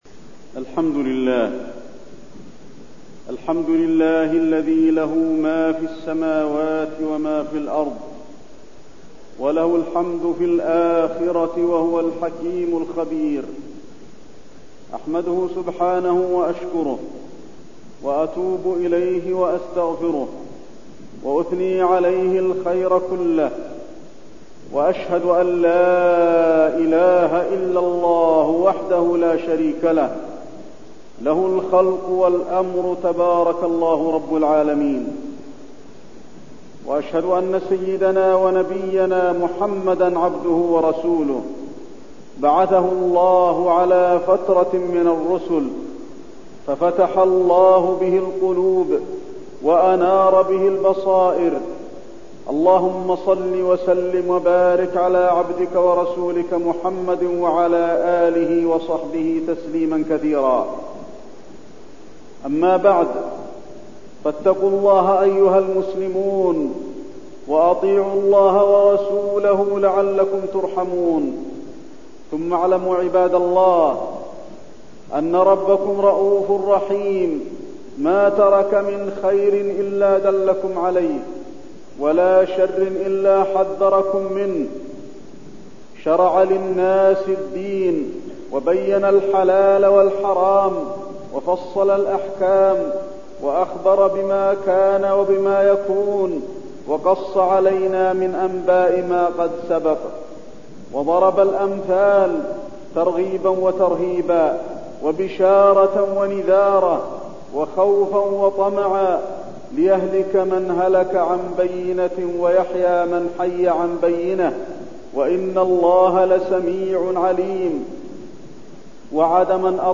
تاريخ النشر ١٦ شوال ١٤٠٧ هـ المكان: المسجد النبوي الشيخ: فضيلة الشيخ د. علي بن عبدالرحمن الحذيفي فضيلة الشيخ د. علي بن عبدالرحمن الحذيفي تحريم الخمر